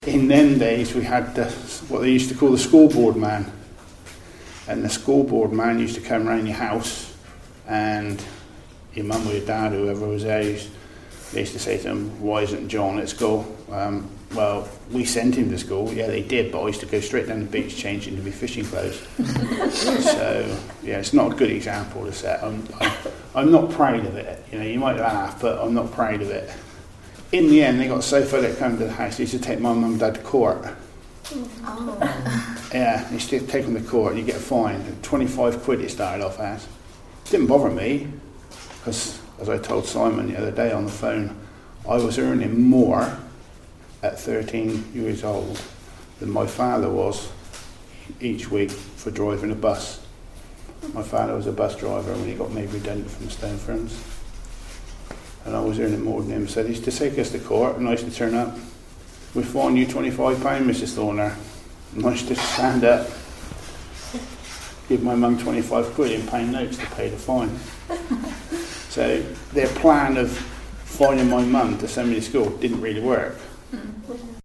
recorded these anecdotes, memories and experiences of Portland people.